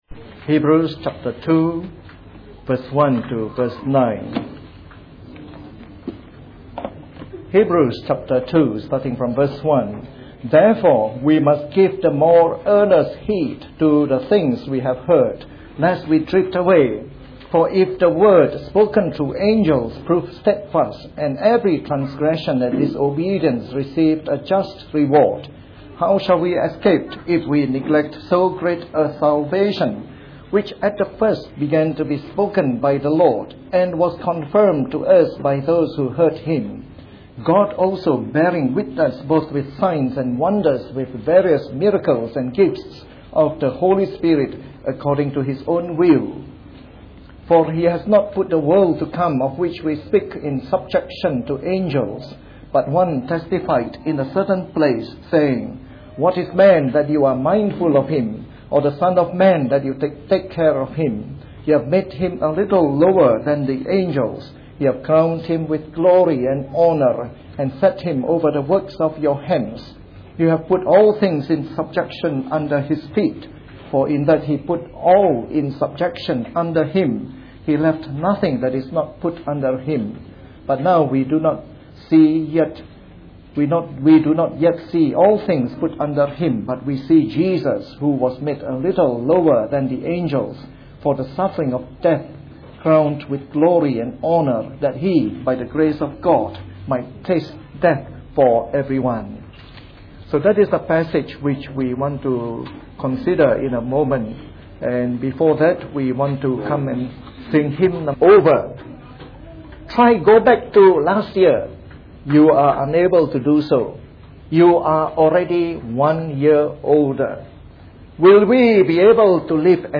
This message was preached on Christmas Day during our yearly Christmas service.